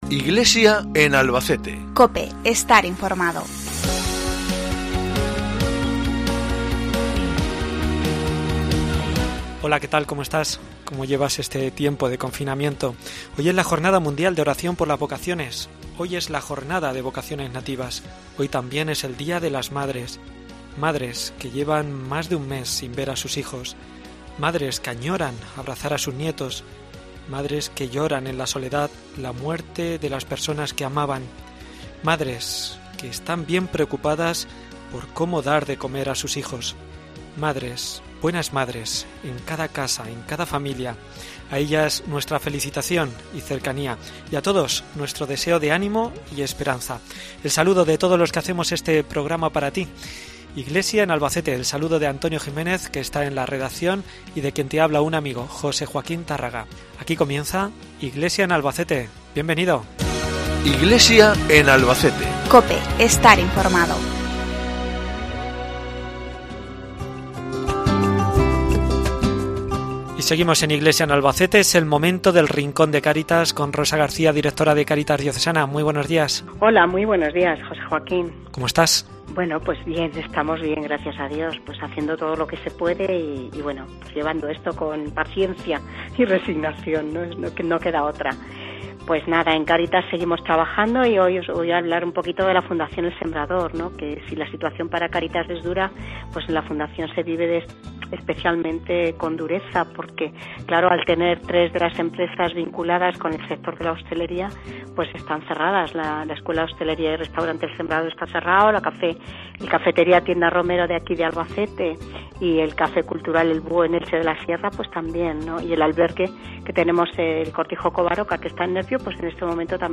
el obispo don Ángel Fernández Collado hoy en el programa